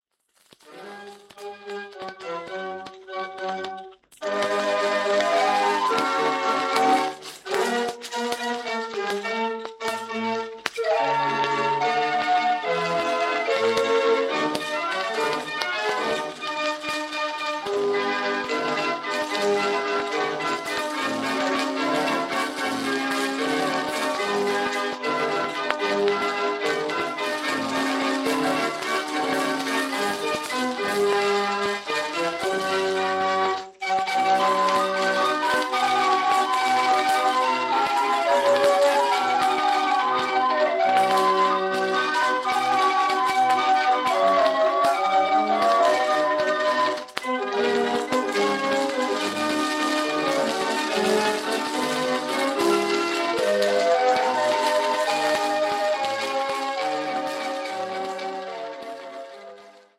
Formaat 78 toerenplaat, schellak